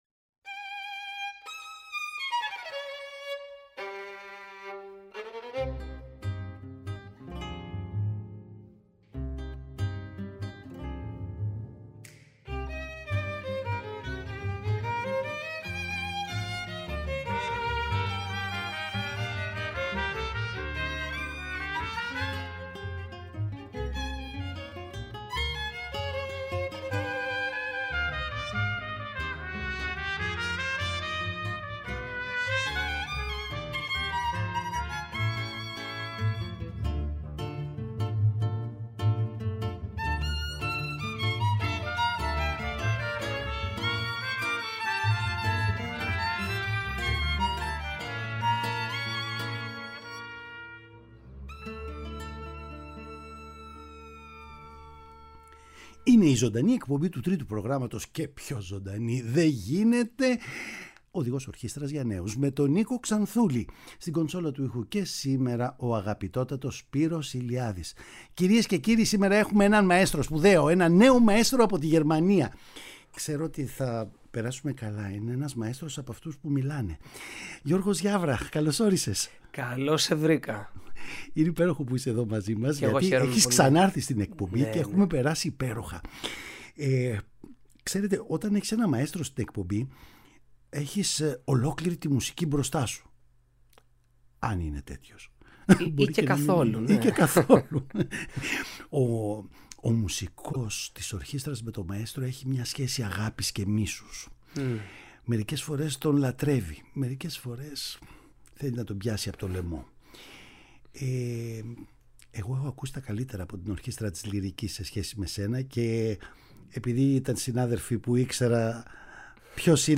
Παραγωγή-Παρουσίαση: Νίκος Ξανθούλης